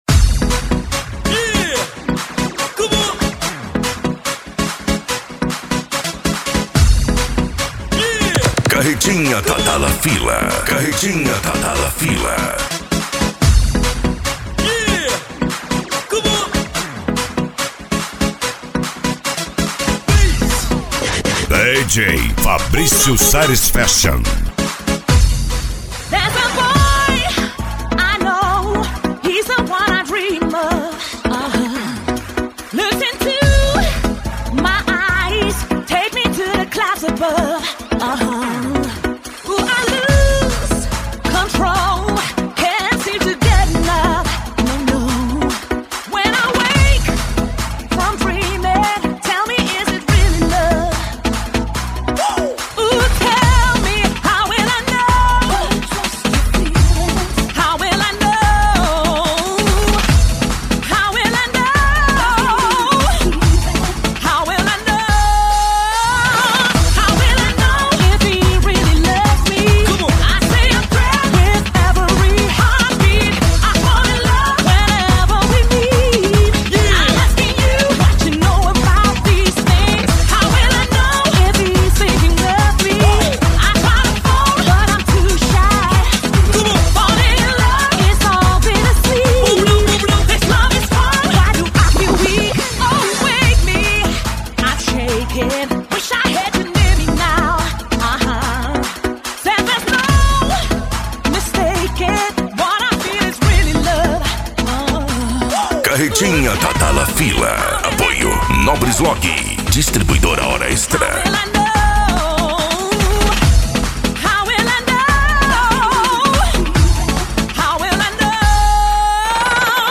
PANCADÃO
Retro Music
SERTANEJO